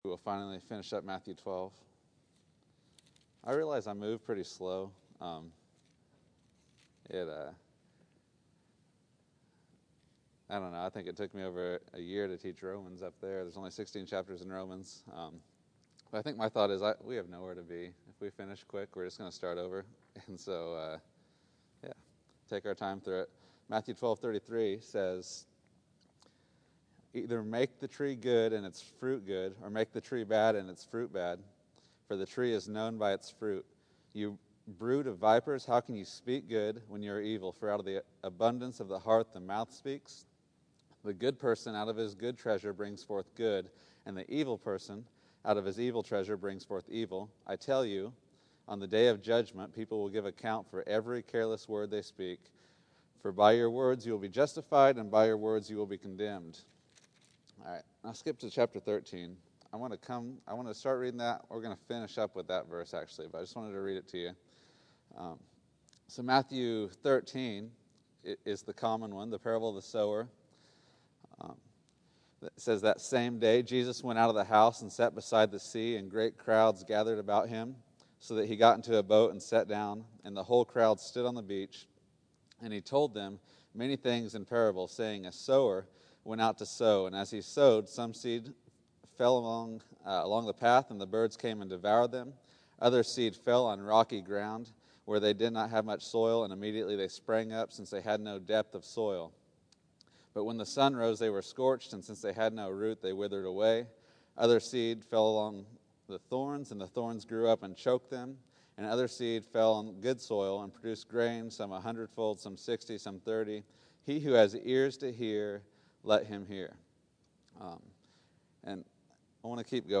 Matthew 13:10-17 September 01, 2013 Category: Sunday School | Location: El Dorado Back to the Resource Library What was the purpose in Jesus using parables?